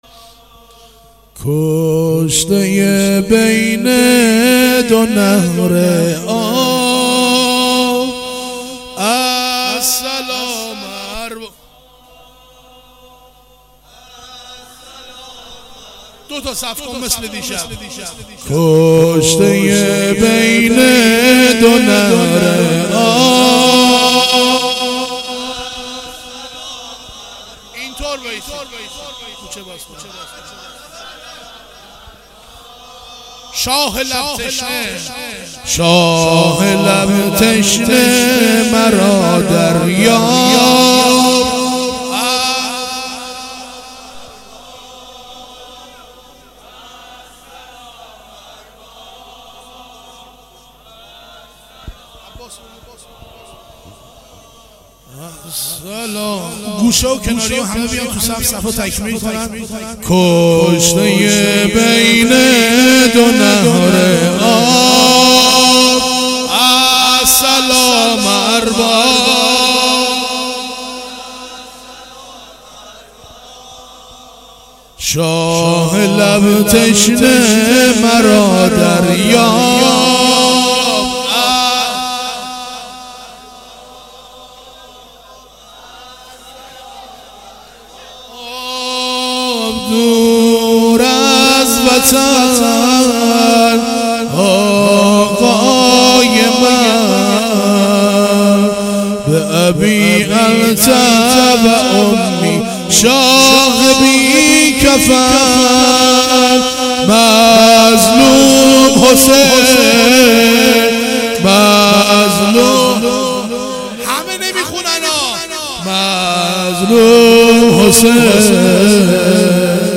زمینه شب سوم محرم 96